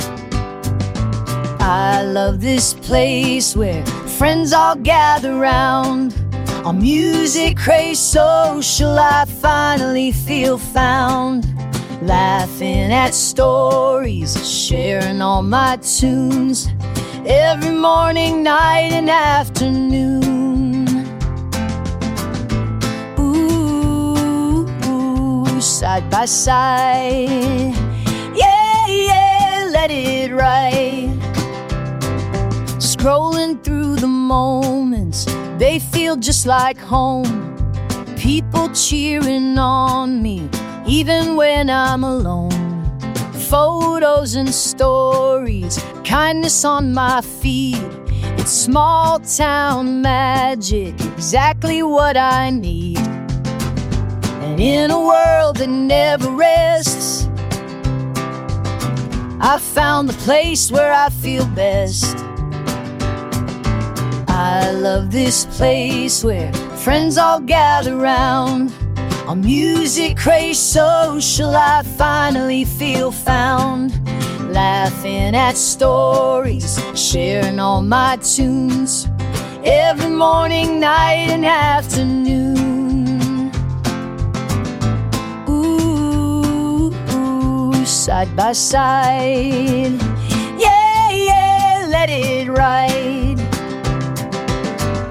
AI music created in the MusicKraze iPhone app
Tags: Country